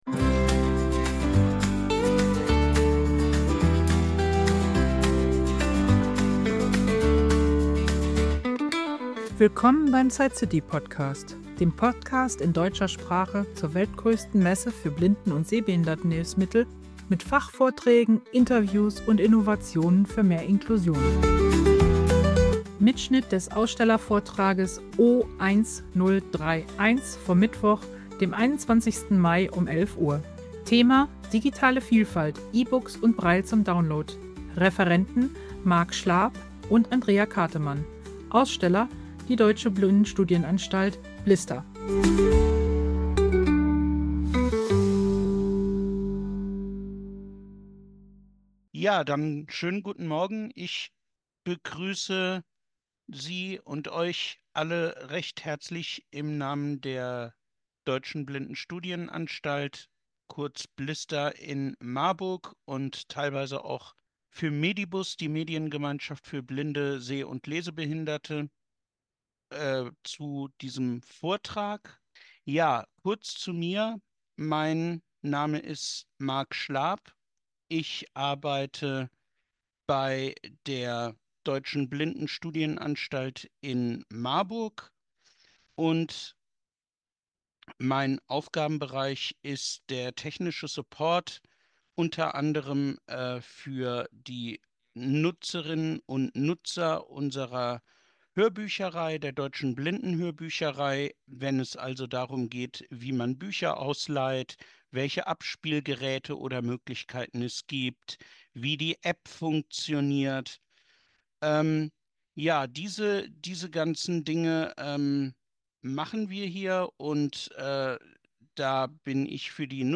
Mitschnitt des Ausstellervortrags O1031 auf der SightCity 2025: Digitale Vielfalt – E-Books und Braille zum Download.